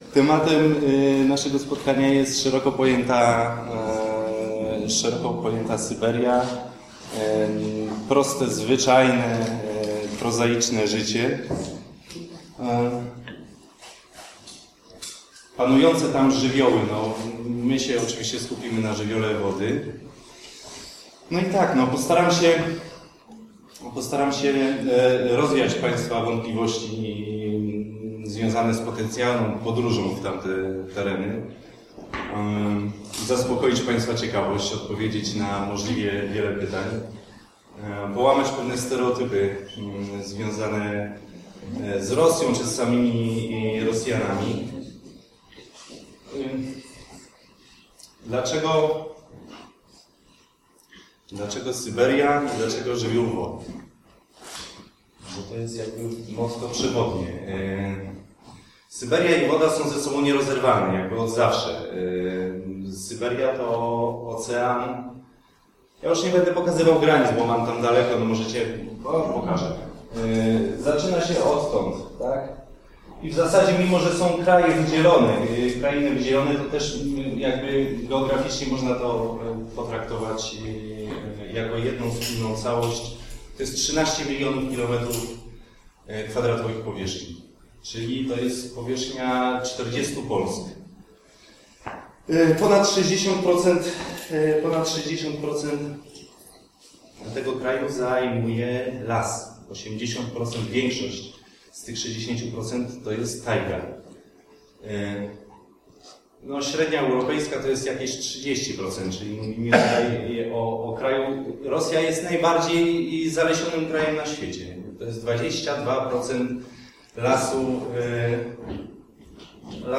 Próbuję wrzucić mp3 z fragmentem spotkania, ale serwer uparcie odmawia „z powodów bezpieczeństwa”.